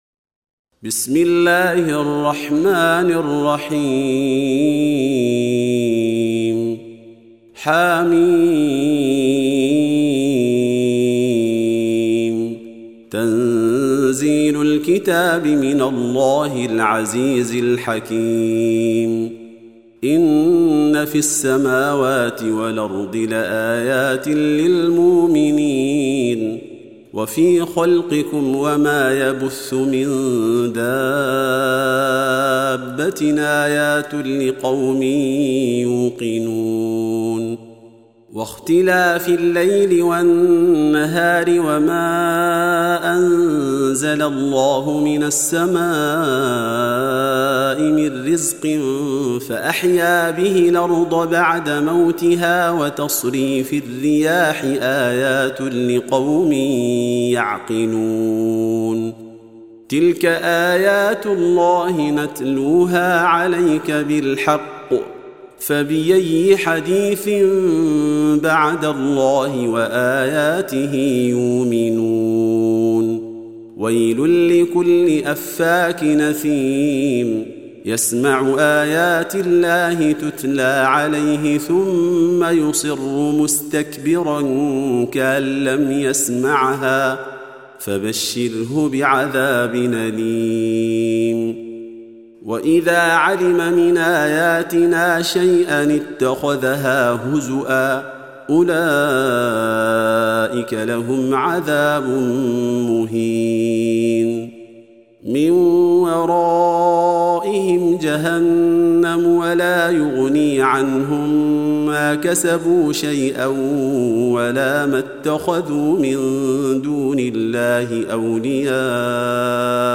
Surah Repeating تكرار السورة Download Surah حمّل السورة Reciting Murattalah Audio for 45. Surah Al-J�thiya سورة الجاثية N.B *Surah Includes Al-Basmalah Reciters Sequents تتابع التلاوات Reciters Repeats تكرار التلاوات